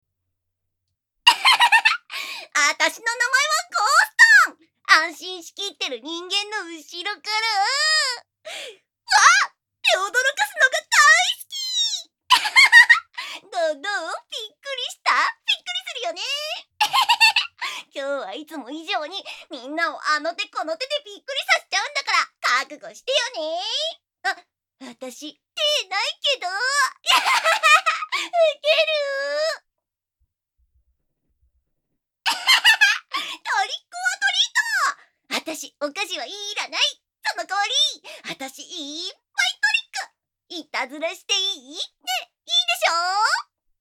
ゴーストの少女。
ゴーストなのに元気いっぱいで天真爛漫な女の子